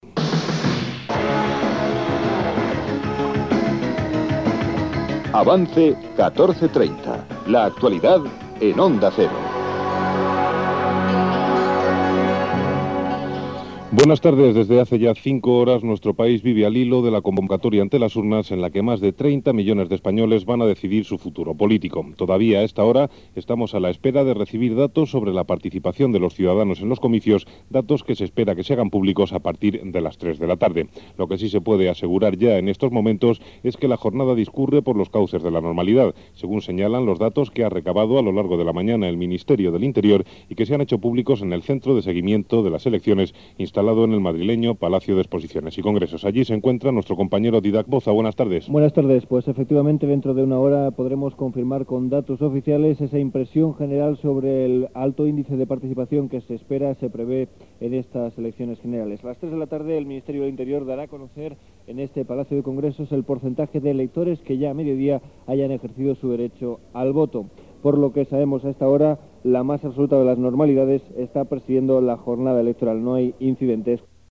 Indicatiu del programa, informació sobre la jornada de les eleccions generals espanyoles
Informatiu